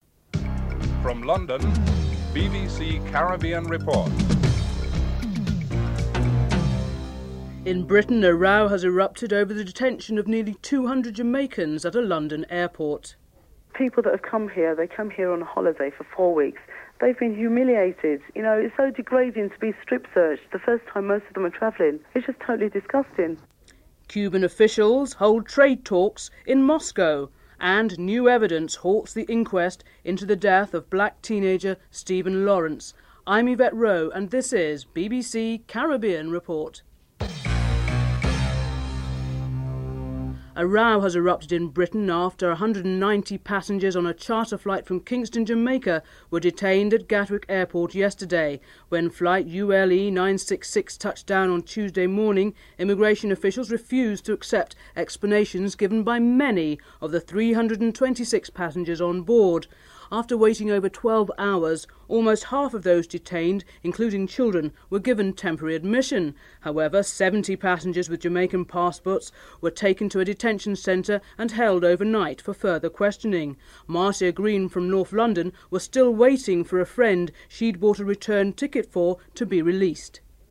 2. Military representatives from four nations are in Haiti to urge the country’s military leaders to restore democracy, and warn army chief Raoul Cédras about the international consequences if he and his allies remain inflexible. President Bill Clinton speaks on the matter